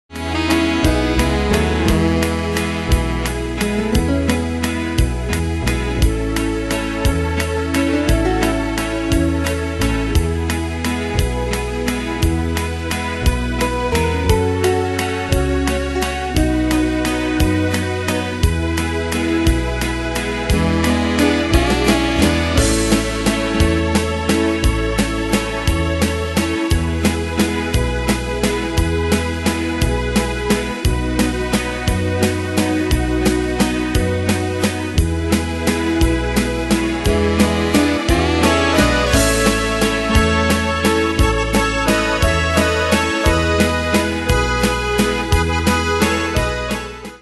Demos Midi Audio
Danse/Dance: Valse/Waltz Cat Id.
Pro Backing Tracks